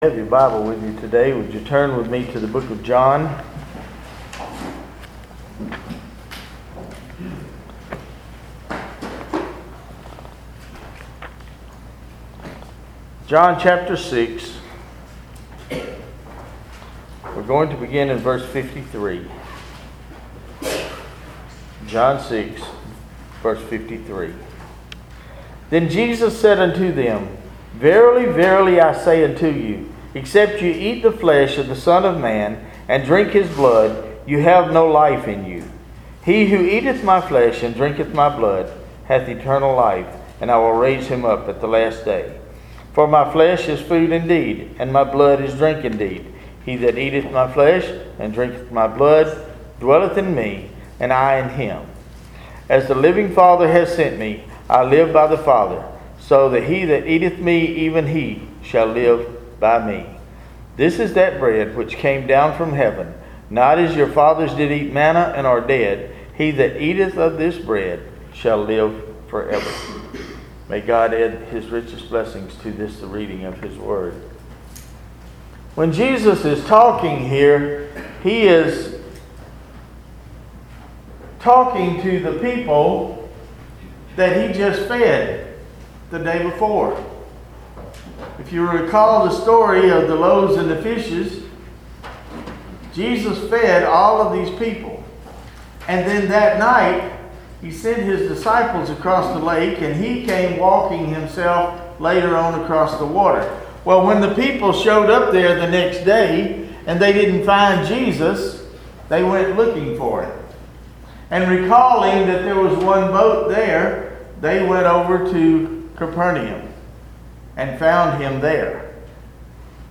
Fitzpatrick United Methodist Worship Services and Sermons